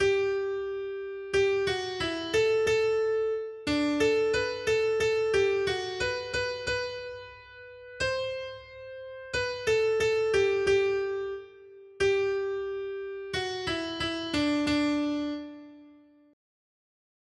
Noty Štítky, zpěvníky ol611.pdf responsoriální žalm Žaltář (Olejník) 611 Skrýt akordy R: Má modlitba, Pane, ať je před tebou jako kadidlo. 1.